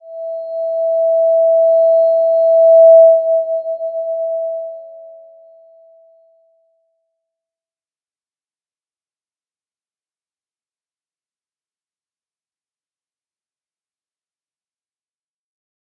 Slow-Distant-Chime-E5-p.wav